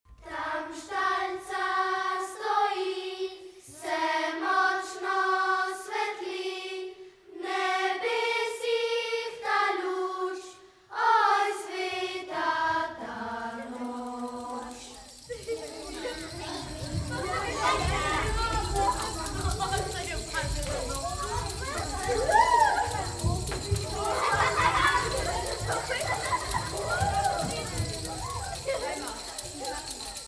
BOŽIČNE IN NOVOLETNE KOLEDNICE S TRŽAŠKEGA